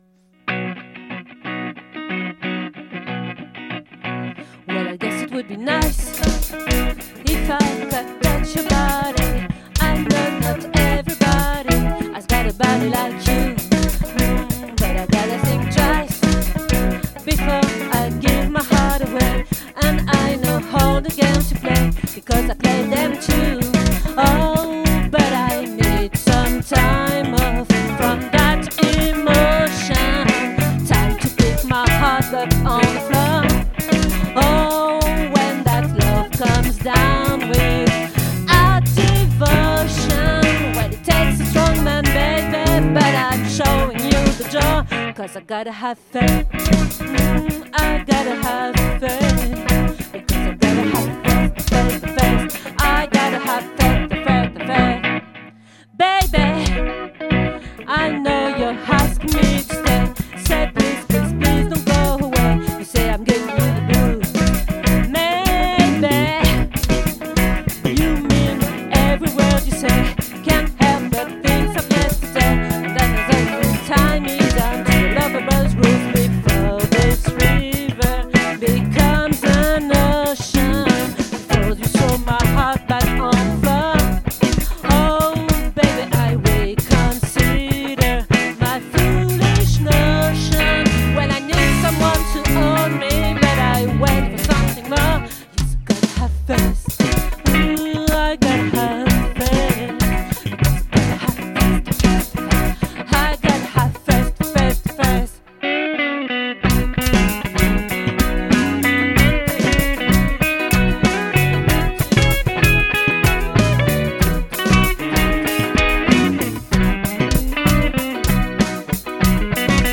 🏠 Accueil Repetitions Records_2022_10_12